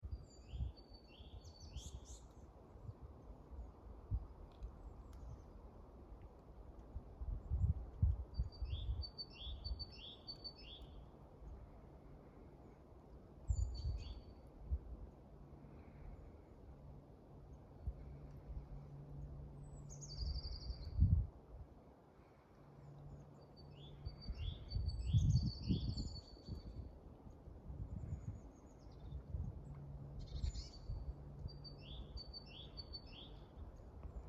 Birds -> Tits ->
Great Tit, Parus major
StatusSinging male in breeding season